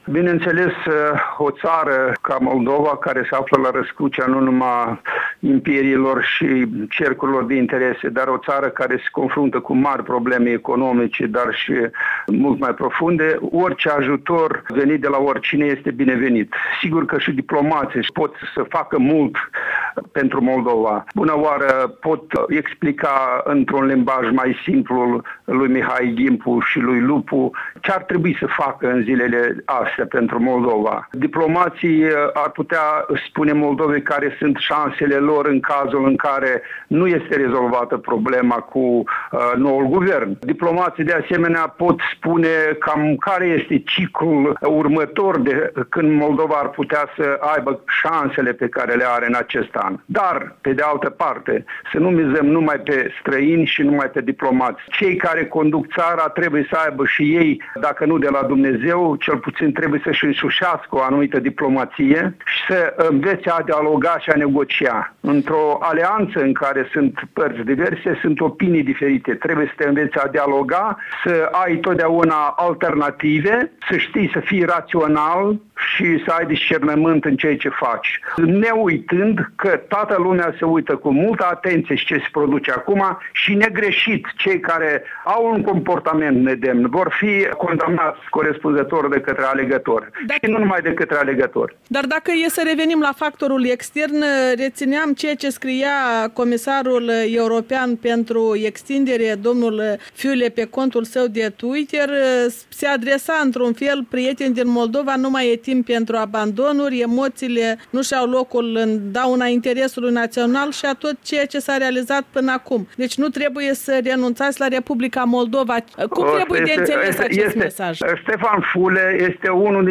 Interviu cu Vasile Nedelciuc